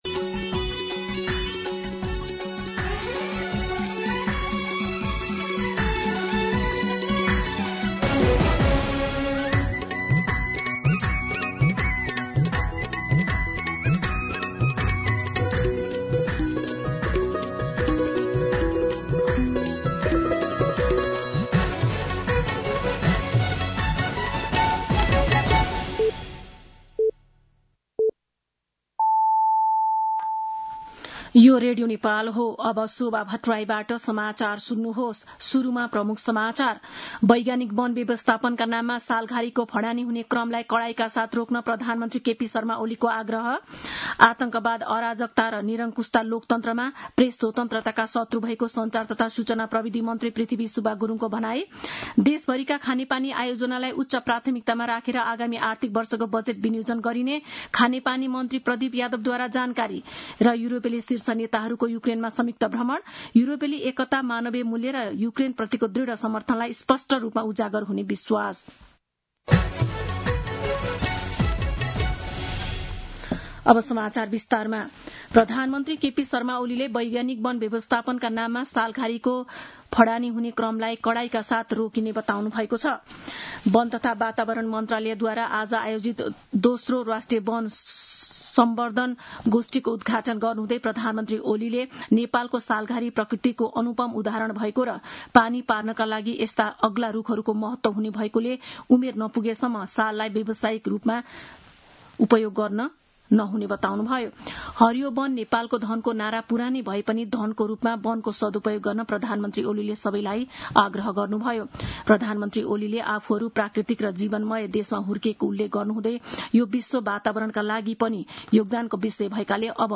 दिउँसो ३ बजेको नेपाली समाचार : २७ वैशाख , २०८२